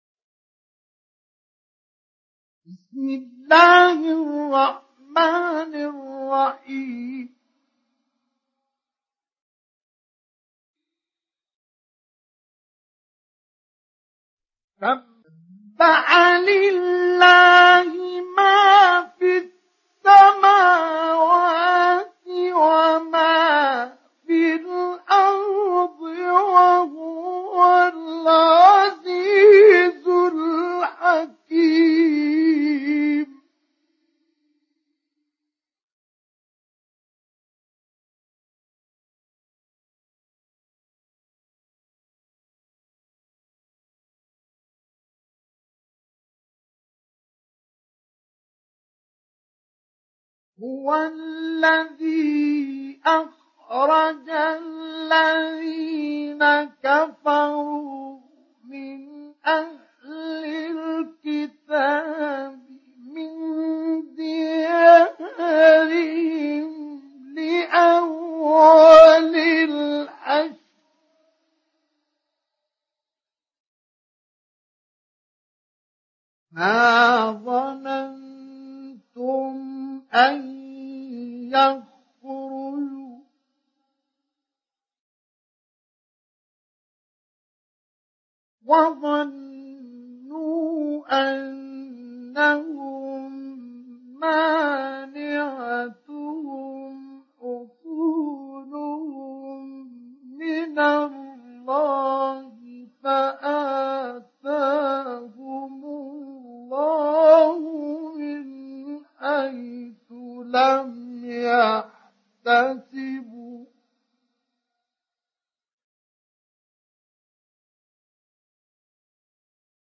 Surat Al Hashr mp3 Download Mustafa Ismail Mujawwad (Riwayat Hafs)
Download Surat Al Hashr Mustafa Ismail Mujawwad